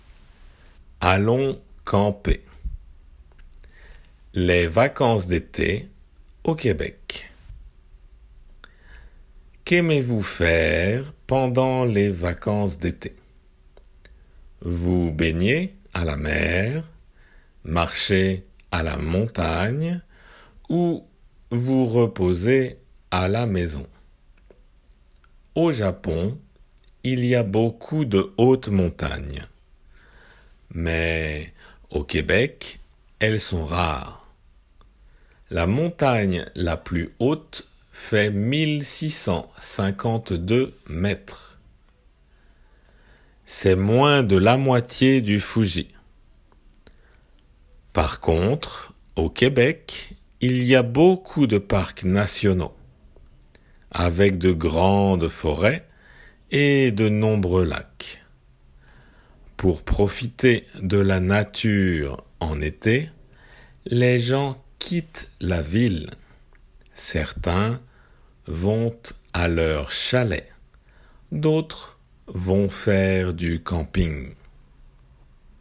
デイクテの練習
普通の速さで